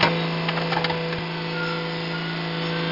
gear.mp3